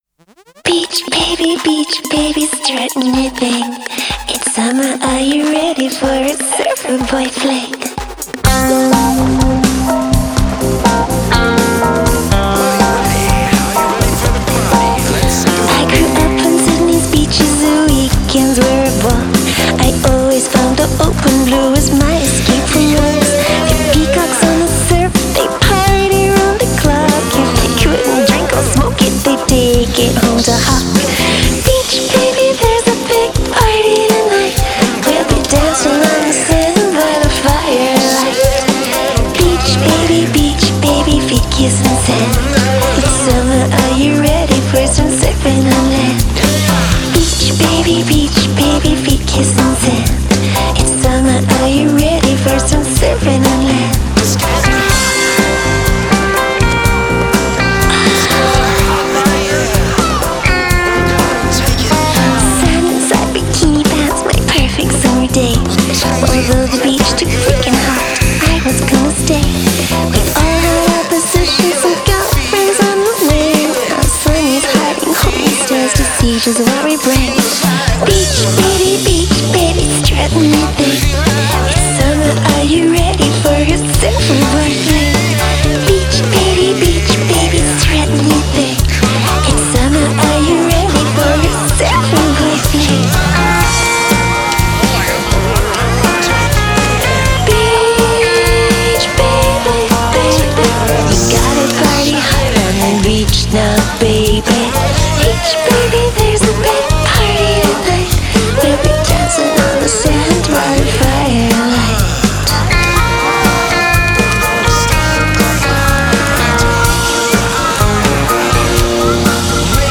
piano and backing vocals
guitar
drums